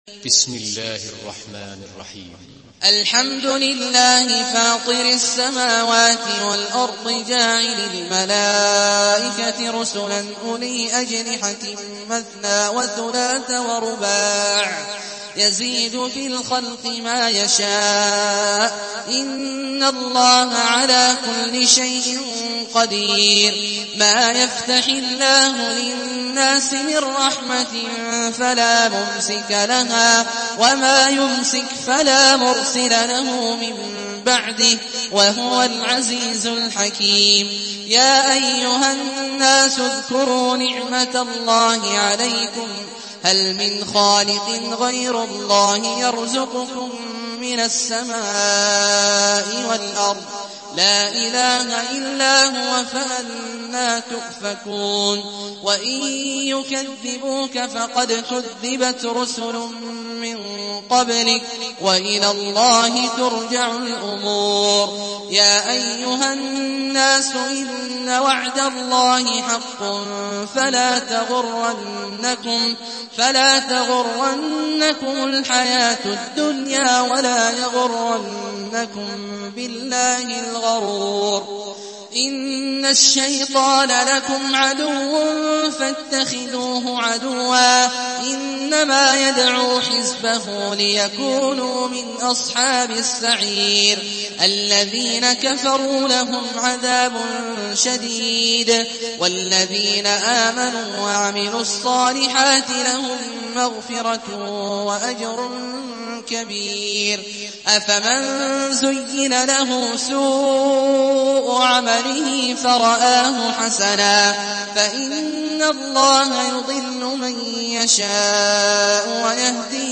Surah فاطر MP3 in the Voice of عبد الله الجهني in حفص Narration
مرتل